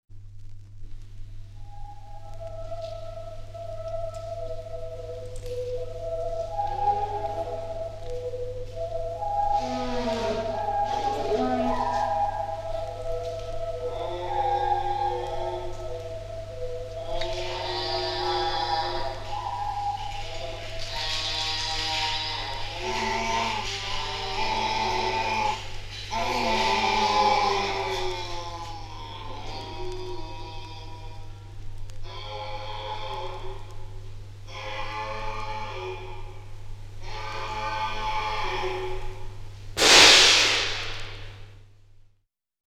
der die Energie von Punk und Rock´n Roll aufschaufelte
REMIXES